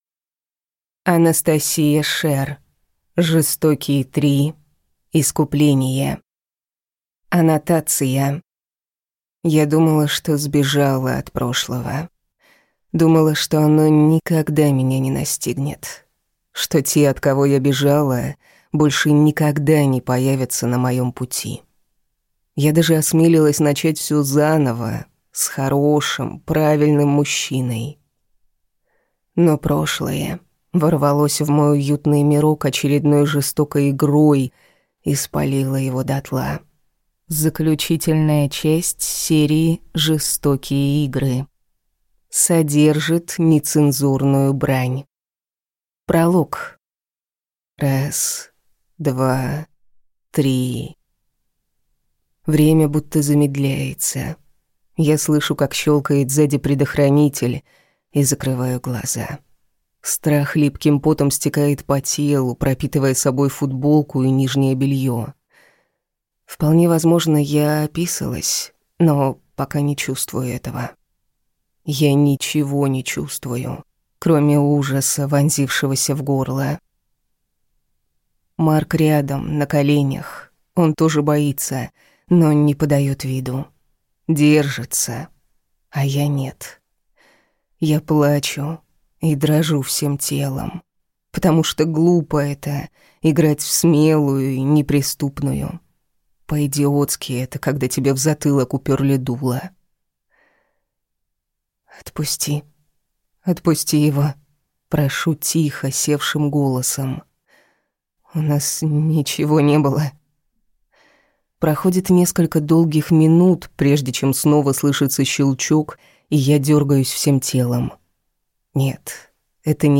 Аудиокнига Жестокий 3. Искупление | Библиотека аудиокниг